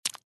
Звук падающей на пол слизи